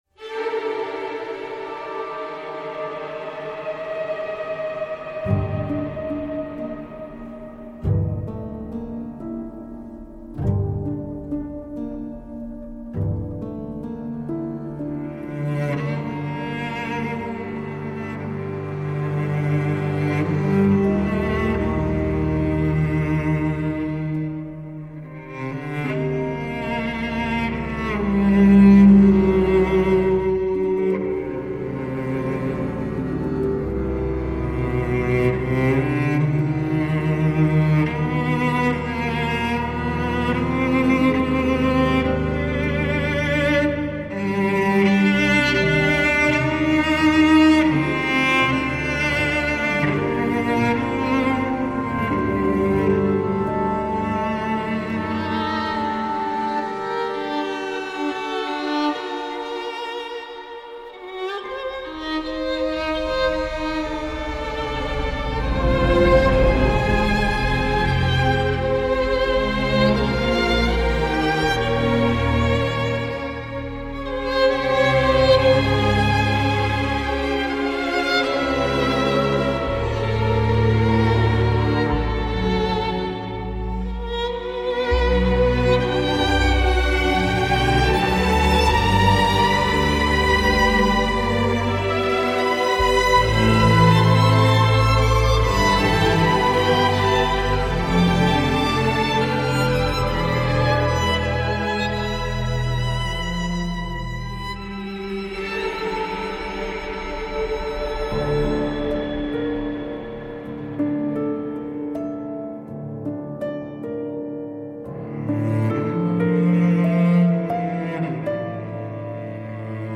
Une partition particulièrement dépressive ?